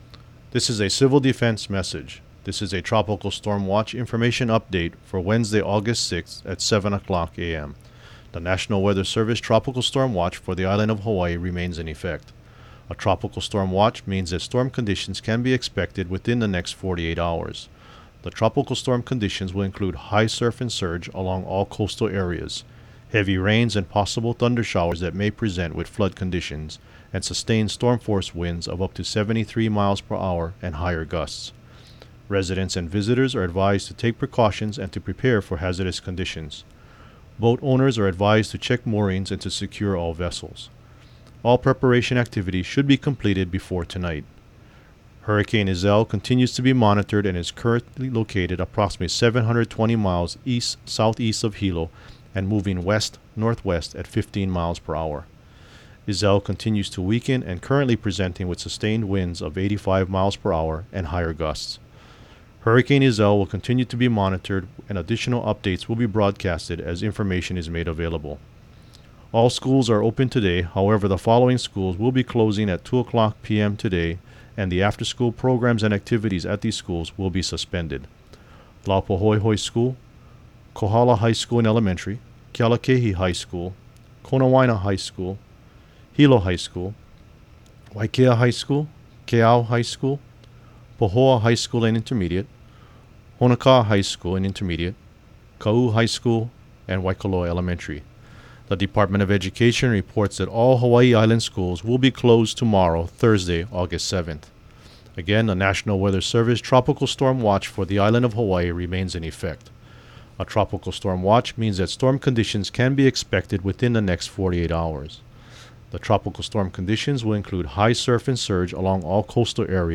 Hawaii County Civil Defense message (Aug. 6, 2014 at 7:15 a.m. HST)